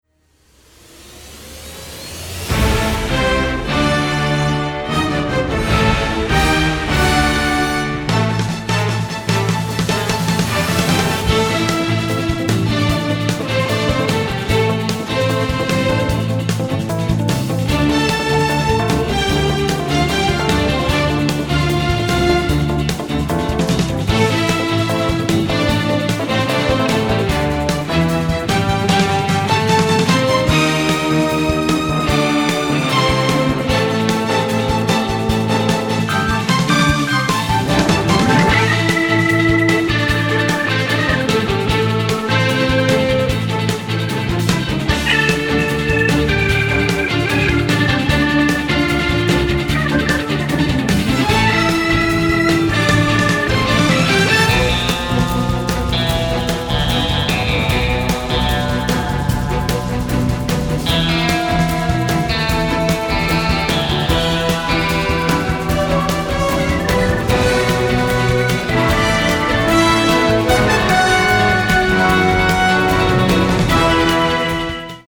dynamic score, written in a symphonic jazz style
Recorded in London